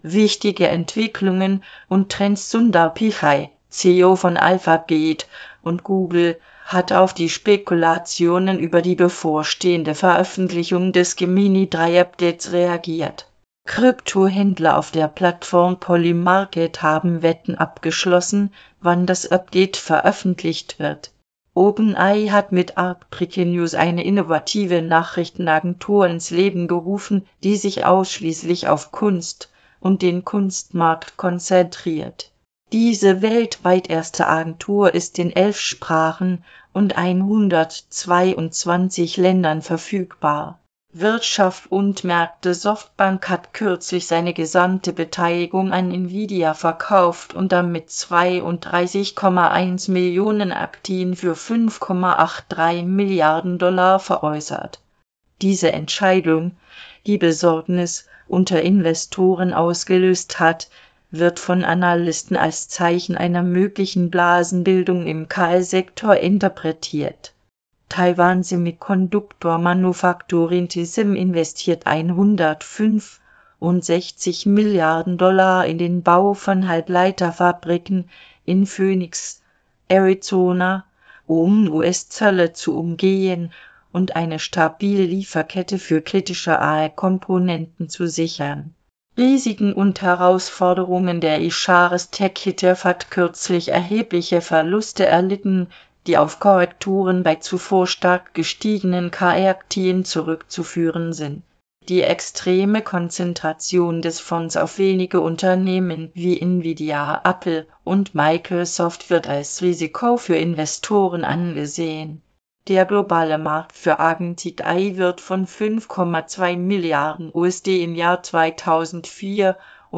Vorlesen (MP3)